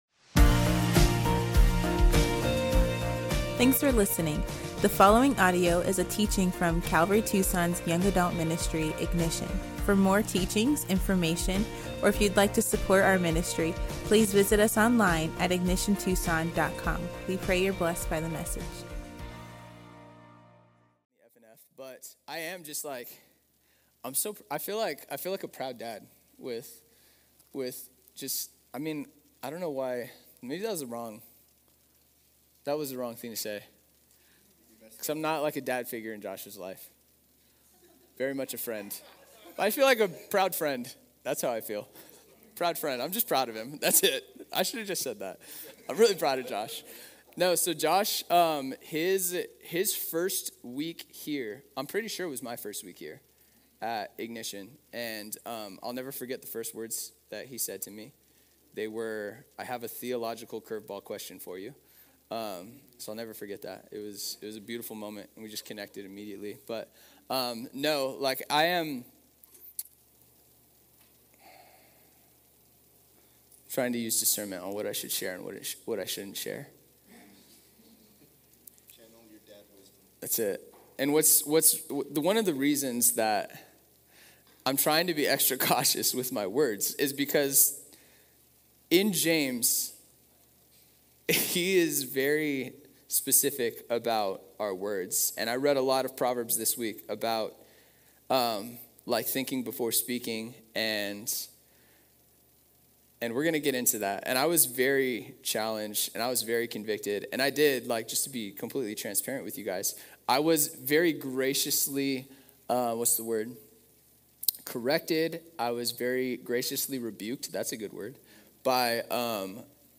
Ignition Young Adults